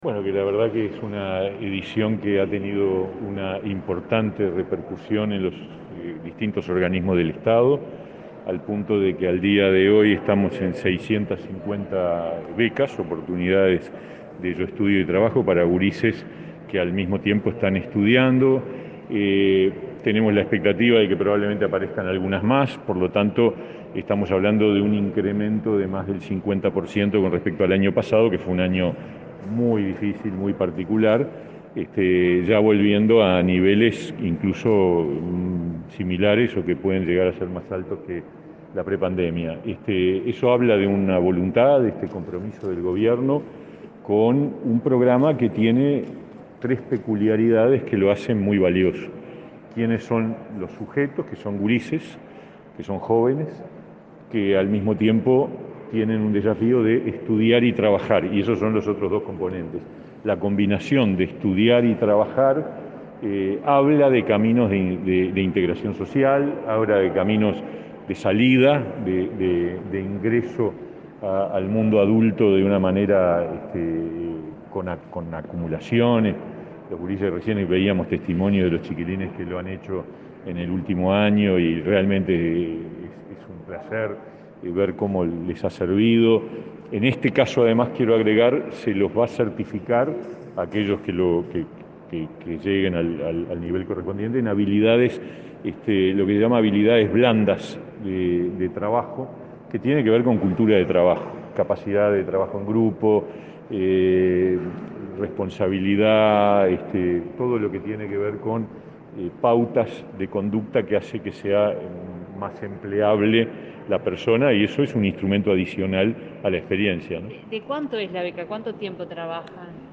Declaraciones a la prensa del ministro de Trabajo, Pablo Mieres